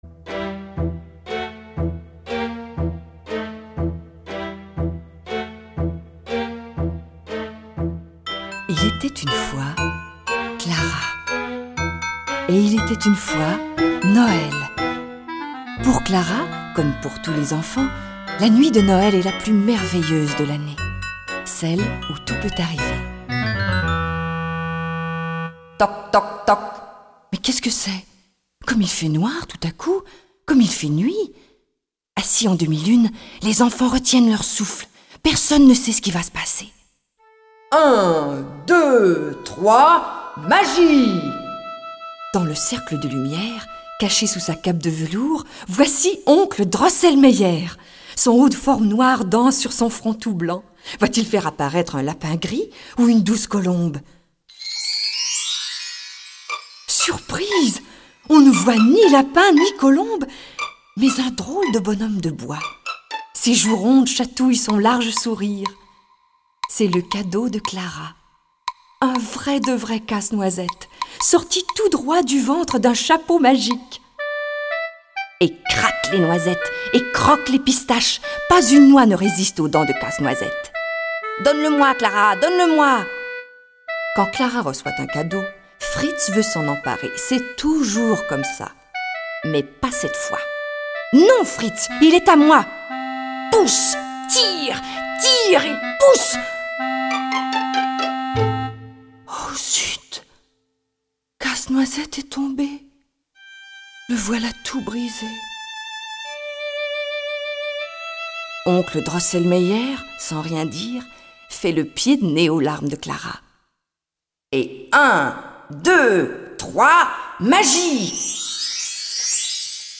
En prenant soin de Casse-Noisette convalescent, Clara découvre les pouvoirs magiques de son jouet. Leur escapade débouche sur un royaume de rêve, peuplé de friandises de toutes sortes. Lu par Anne Dorval Durée : 12min